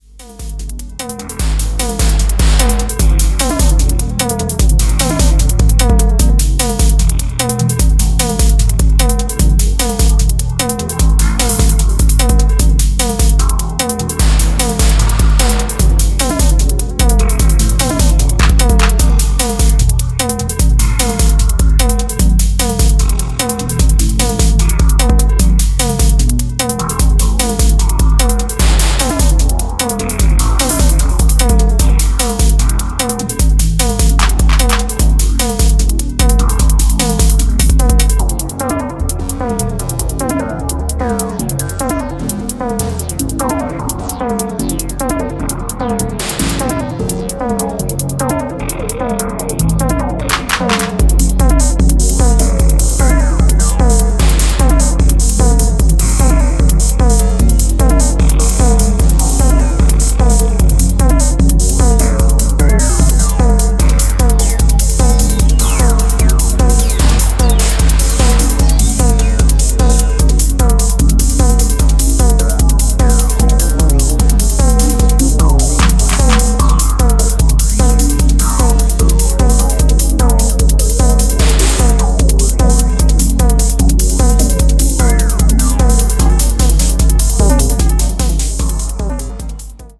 Smooth playful electronics
recorded live